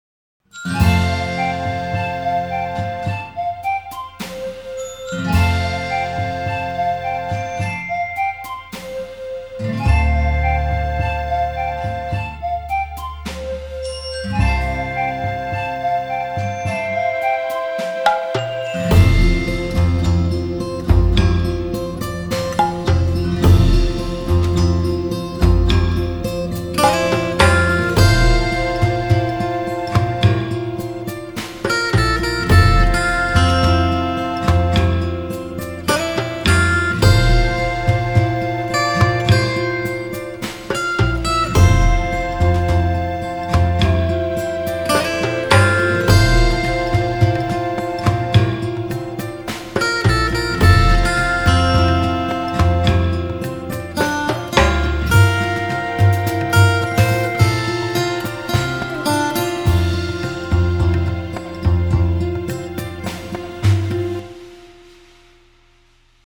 アレンジ
入りはなかなか好きだけど、後の展開が思いつかないのでボツ